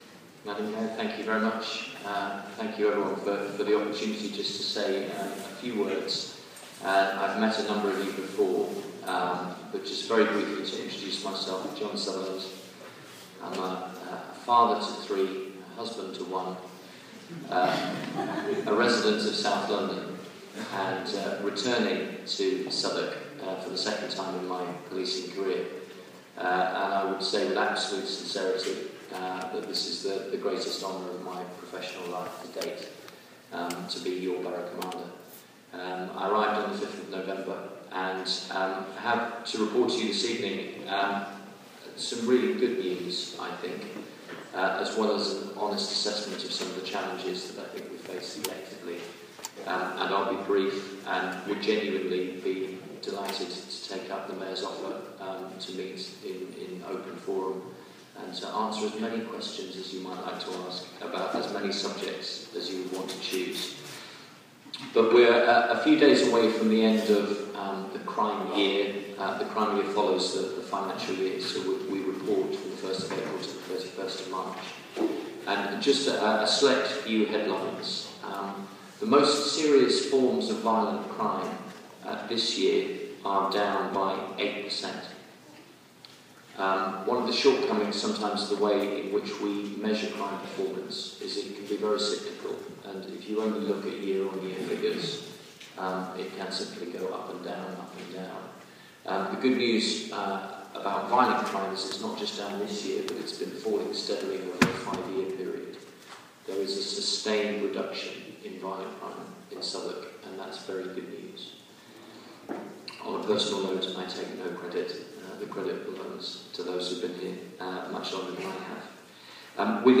Southwark borough police commander John Sutherland addresses council assembly